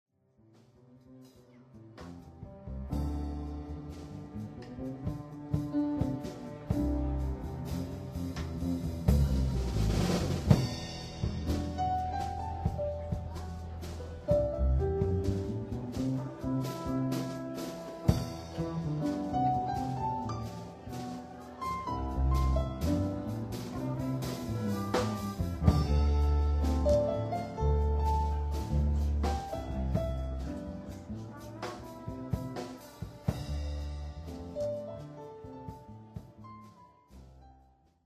groupe de jazz fusion